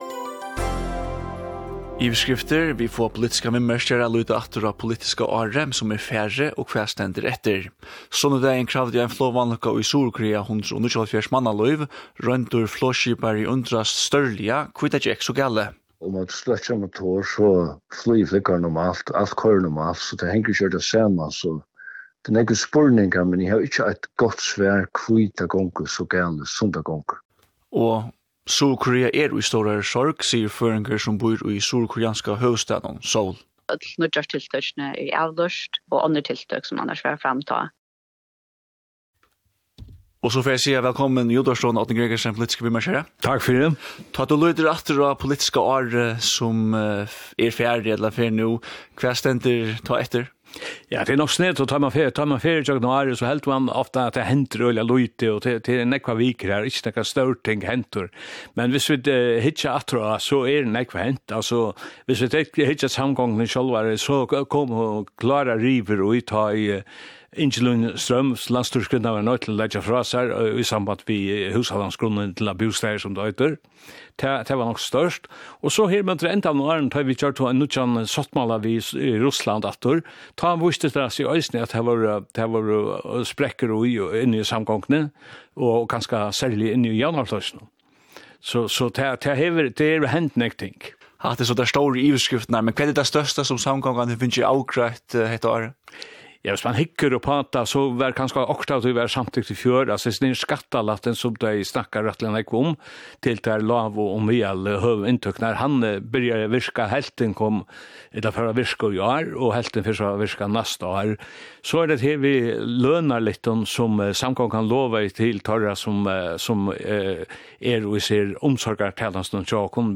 … continue reading 50 episoder # faroe islands # News # Kringvarp Føroya